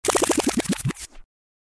backspin.mp3